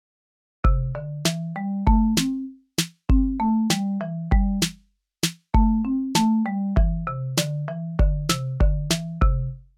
Como vedes, soamente empregaremos cinco notas: DO-RE-MI-SOL e LA.
Melodía pentatónica
Por certo, etas foron as notas empregadas para darlle forma a esta melodía pentatónica.
MELODIA_PENTATONICA.wav